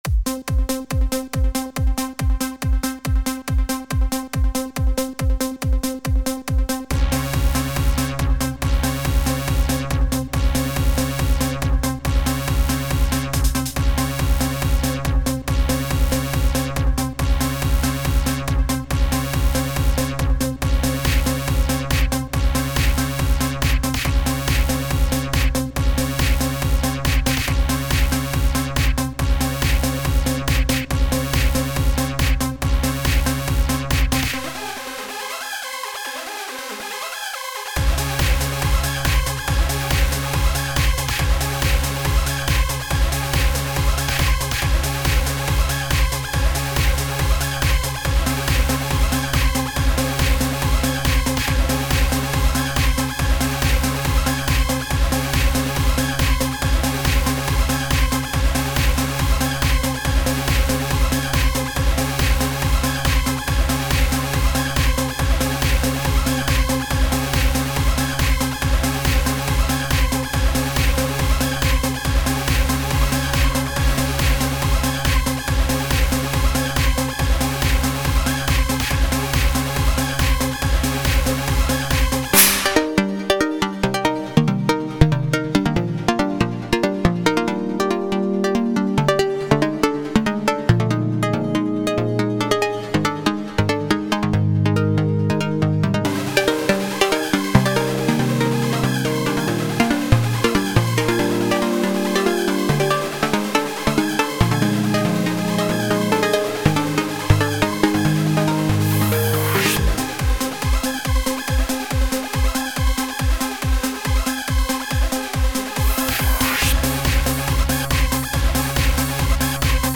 Стиль: Hardcore